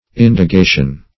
Meaning of indagation. indagation synonyms, pronunciation, spelling and more from Free Dictionary.